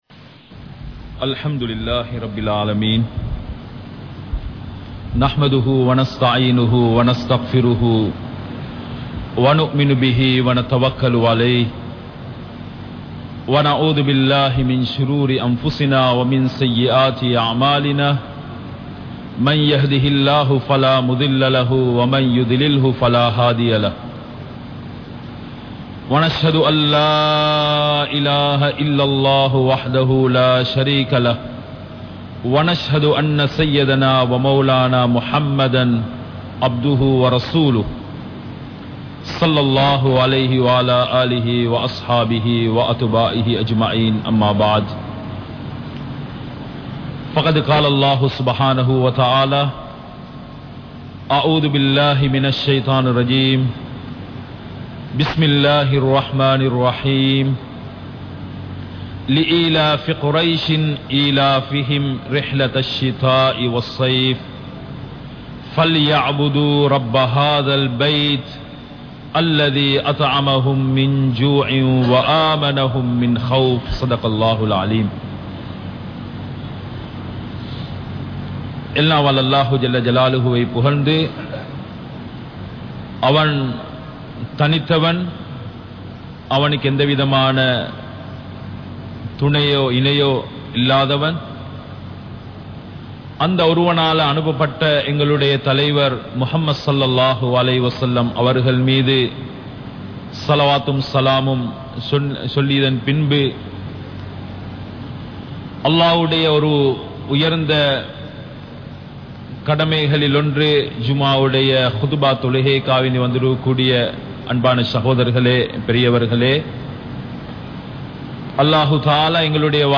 Matravarhalin Urimaihal | Audio Bayans | All Ceylon Muslim Youth Community | Addalaichenai
Colombo 03, Kollupitty Jumua Masjith